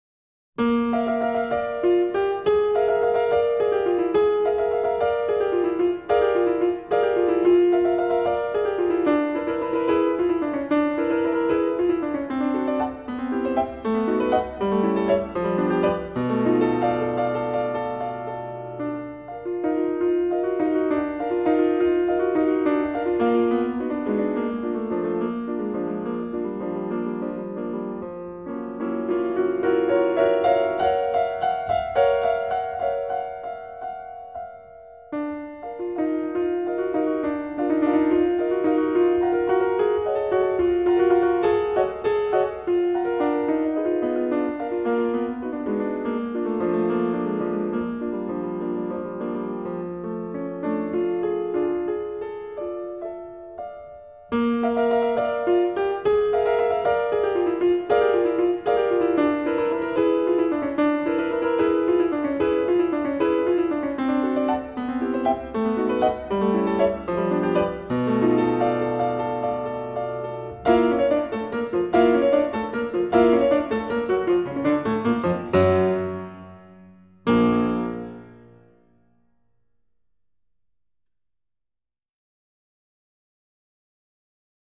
0215-钢琴名曲铃鼓.mp3